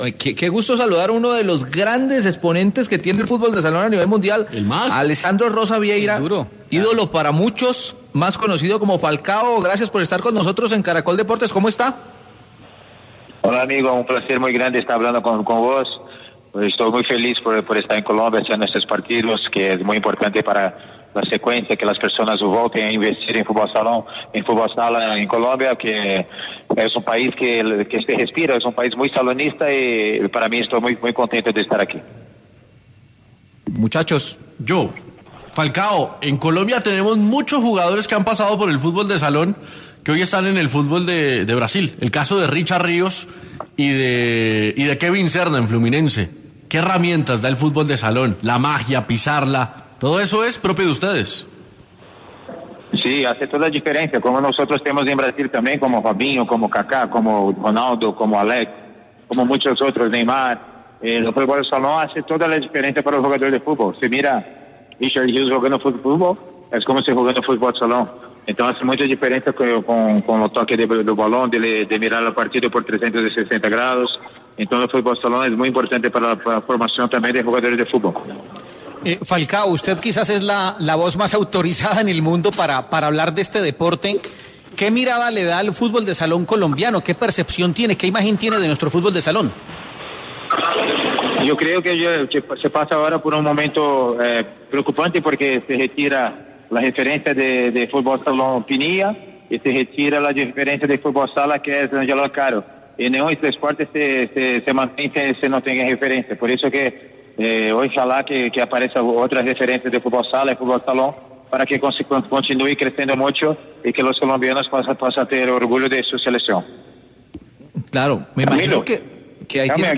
A la charla se sumó Alessandro Rosa Vieira, más conocido como Falcão, quien es considerado por muchos como el mejor jugador de futsal en toda la historia.